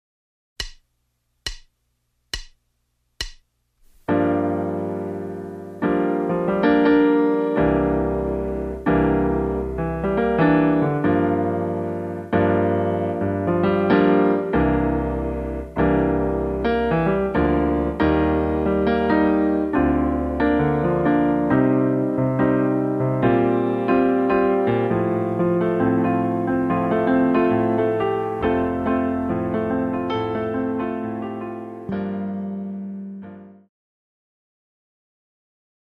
Karaoke Soundtrack
Backing Track without Vocals for your optimal performance.